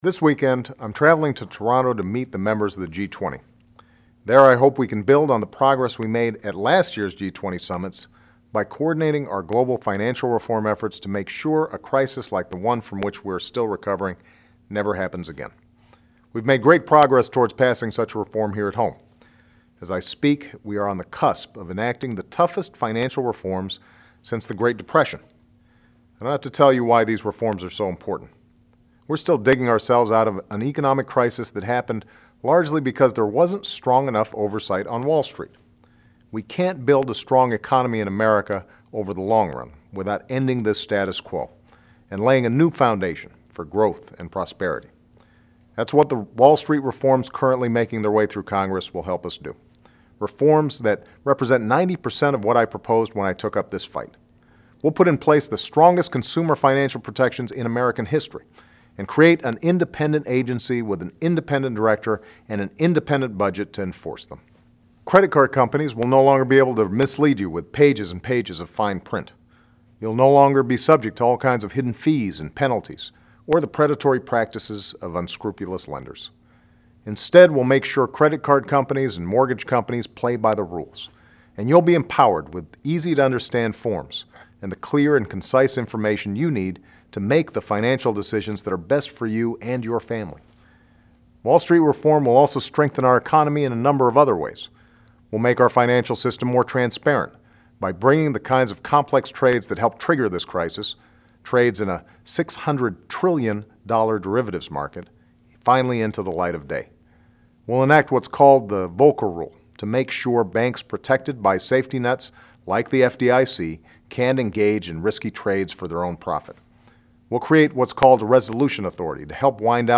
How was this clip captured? Washington, DC